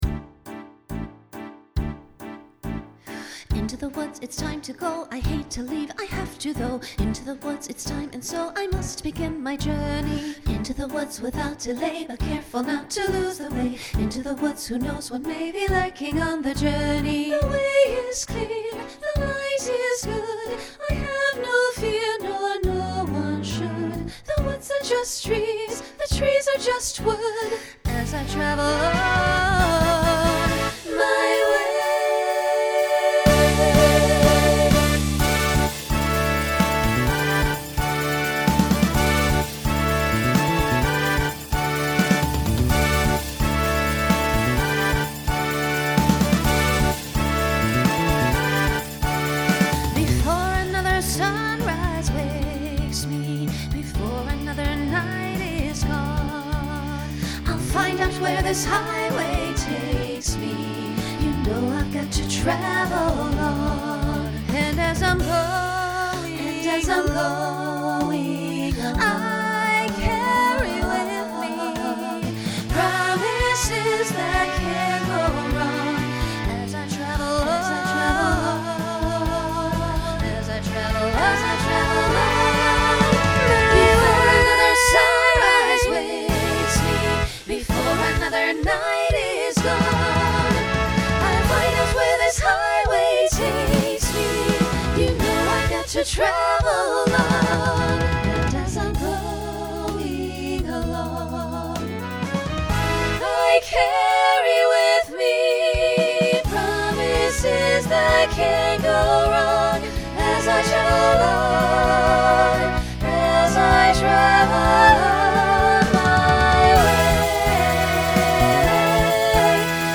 Genre Broadway/Film Instrumental combo
Show Function Opener Voicing SSA